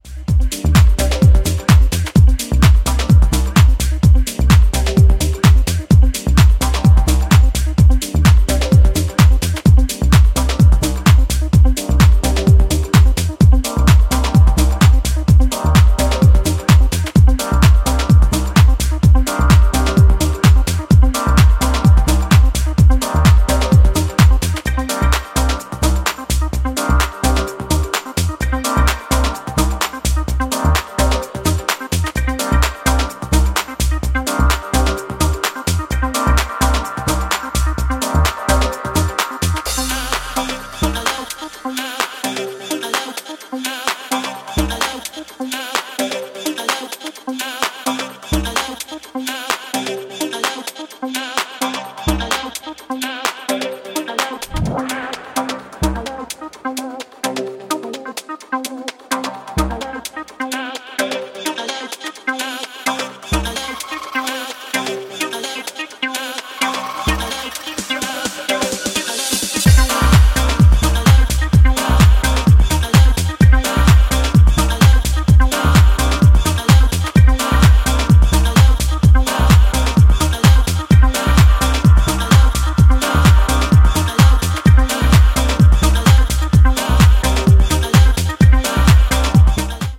リフレインするヴォイスサンプルとサブリミナルなアシッドを配したソリッドなテック・ハウス